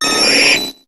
Cri de Roucoups dans Pokémon HOME.